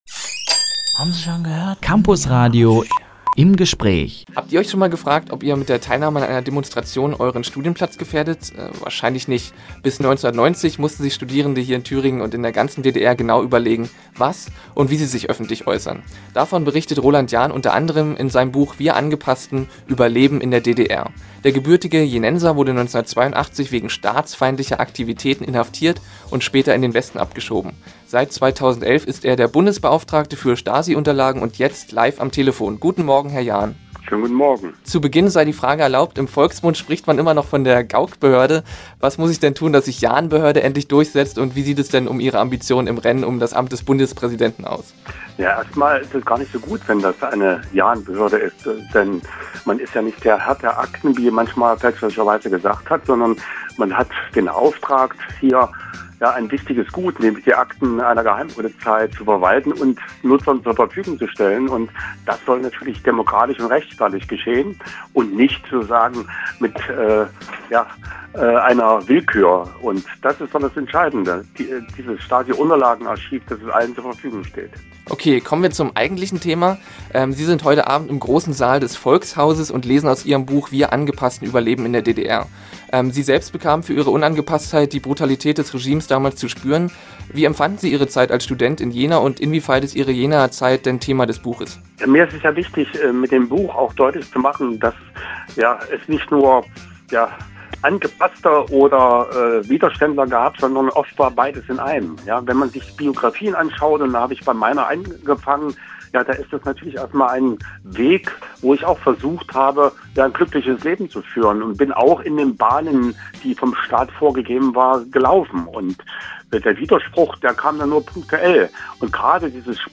IM GESPRÄCH: ROLAND JAHN – Campusradio Jena
Bevor er zur Lesung und zu Diskussionen ins Volkhaus lädt, hat er sich Zeit für ein Gespräch mit uns genommen.